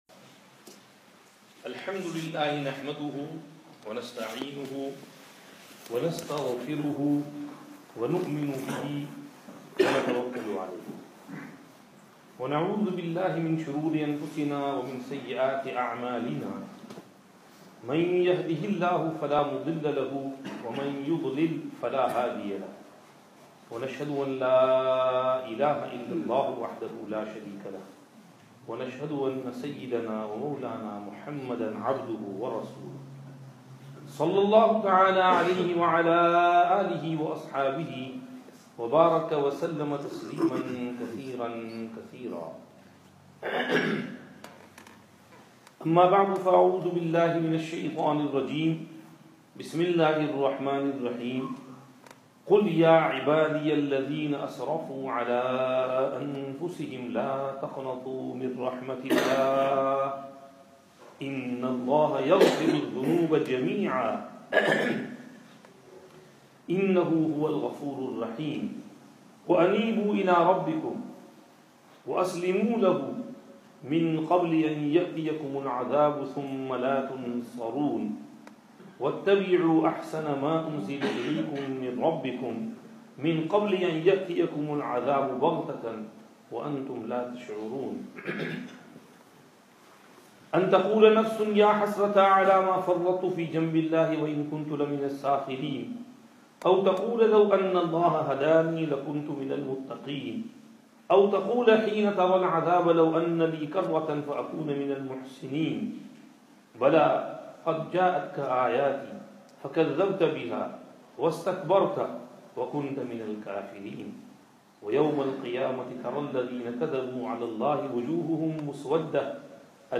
Jumuah Khutbah